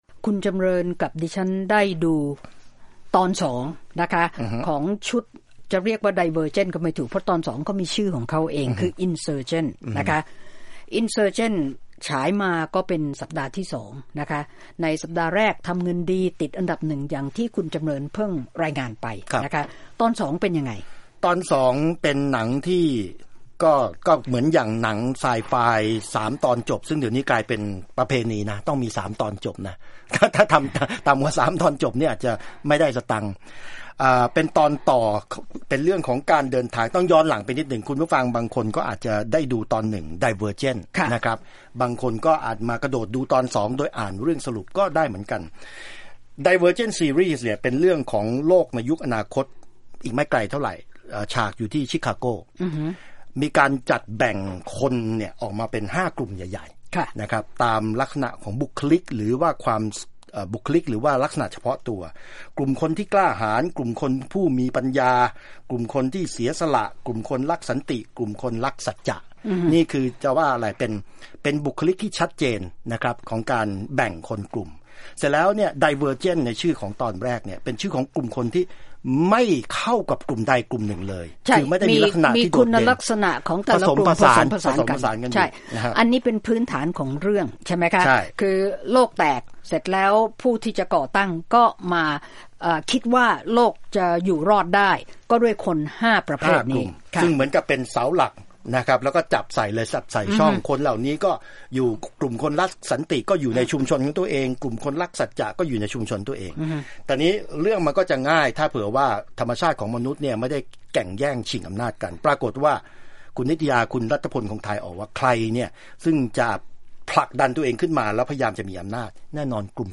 Movie Review Insurgent